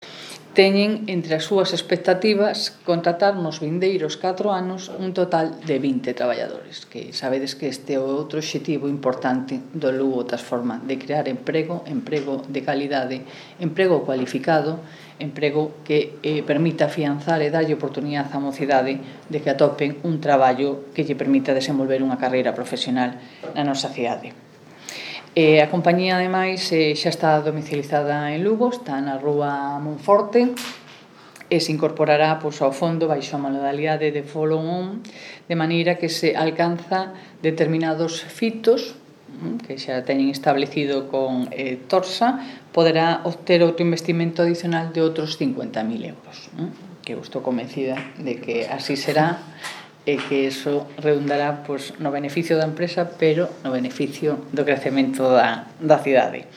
• La alcaldesa de Lugo, Lara Méndez, sobre la presentación de Monkey Markets |